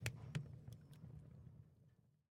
fire_crackle4.ogg